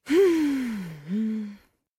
Женский голос: мысли спутались, а сомнения защемили сердце